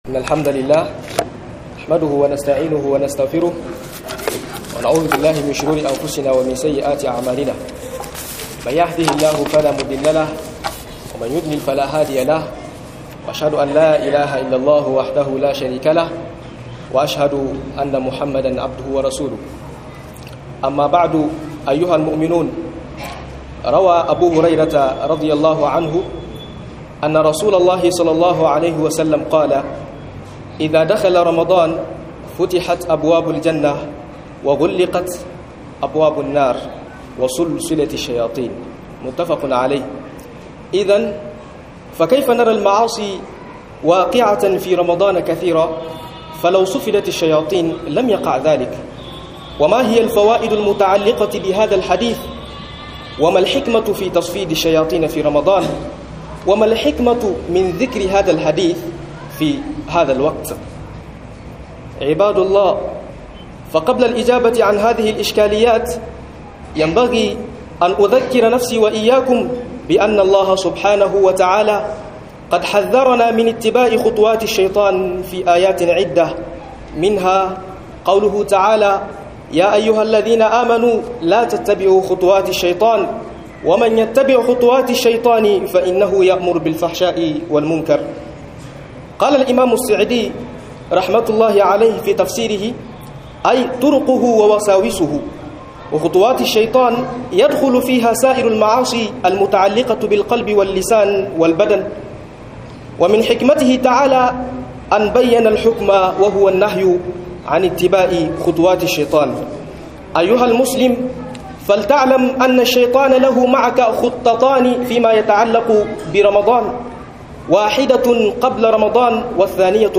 Mi yakamata kamin Ramadan - MUHADARA